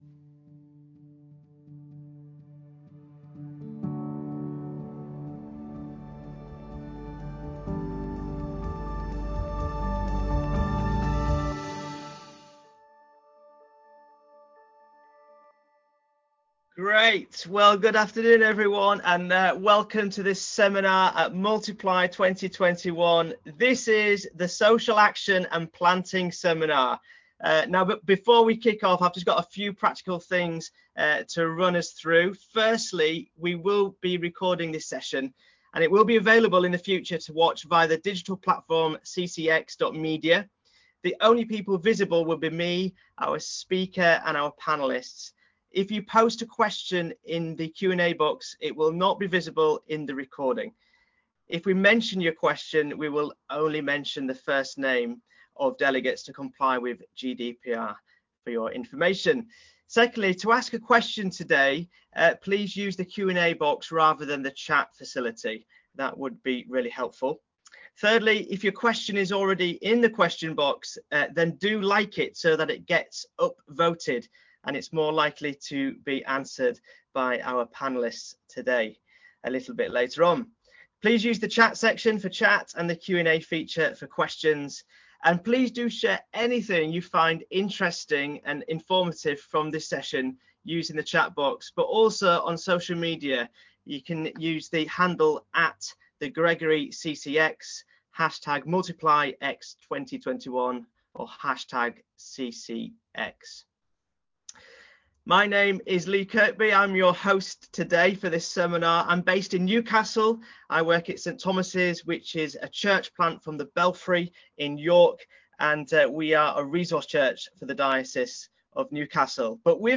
Multiply 2021 Seminars: Social action and planting - CCX